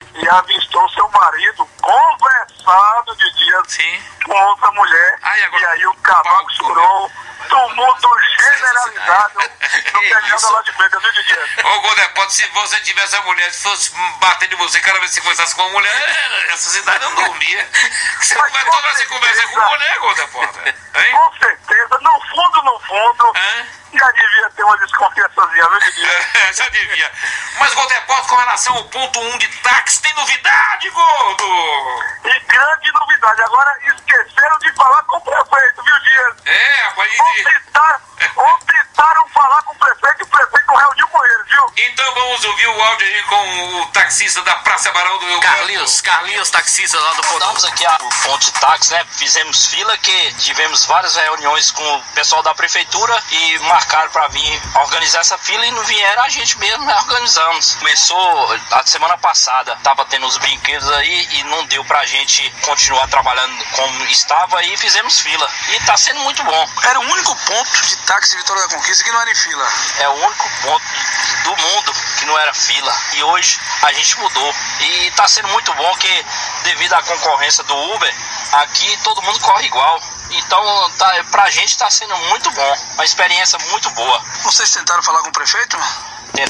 plantão policial